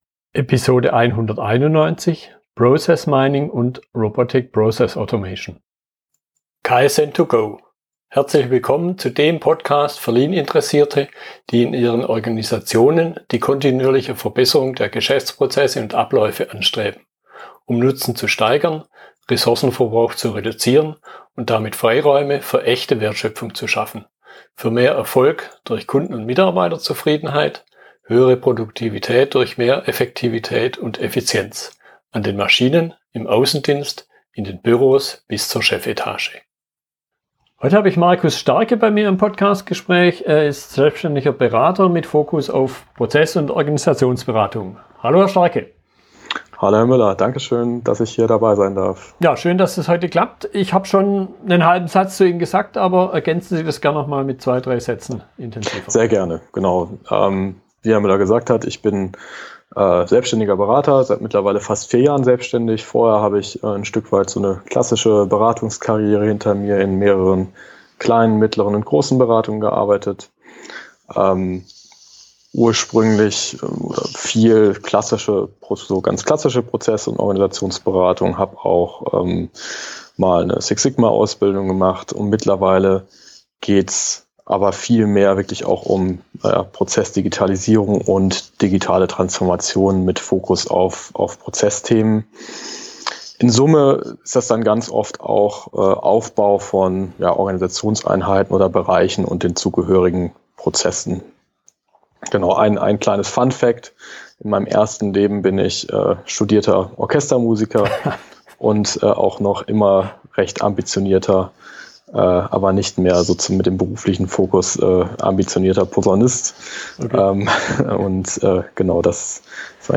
Fragestellungen in der Unterhaltung